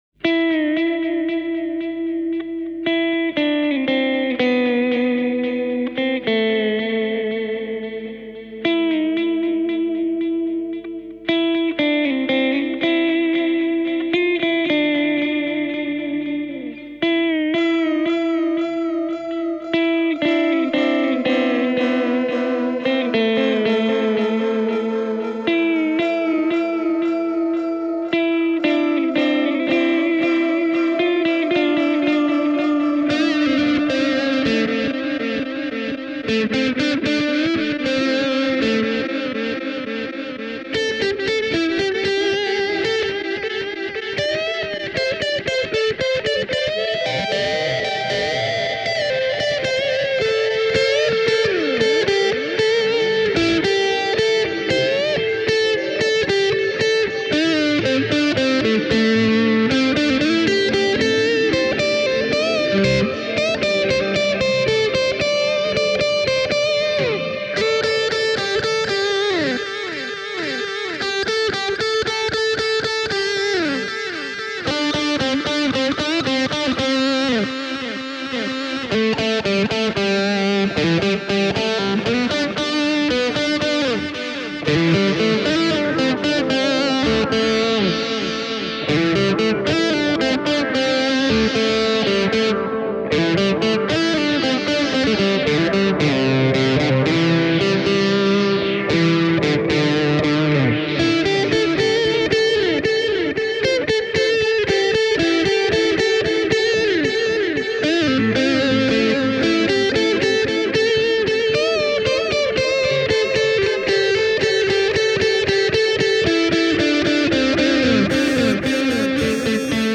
The demo track features 12 different factory patches on the lead guitar, plus an additional patch, used to simulate a violin sound.
Here’s a mix of just the lead guitar parts: